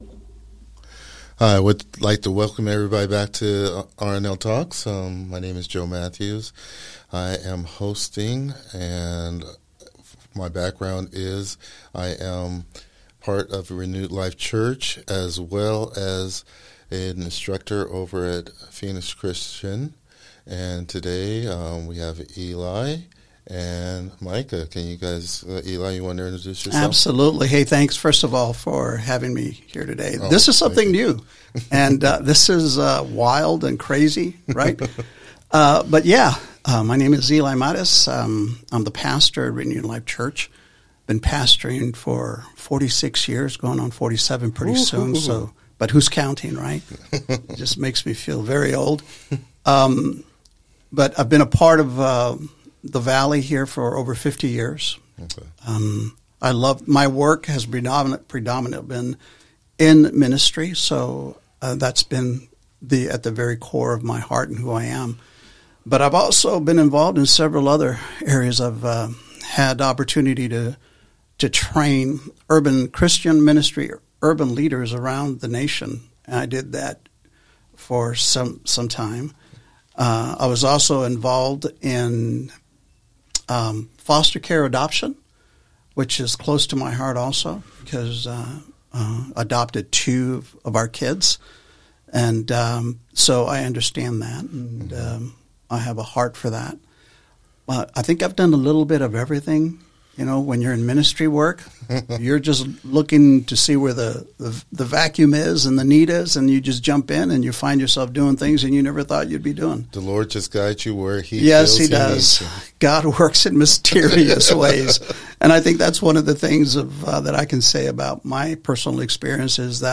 Whether you're a parent, pastor, or part of Gen Z yourself, this conversation will challenge and encourage you to see how God is moving through a generation hungry for purpose and truth.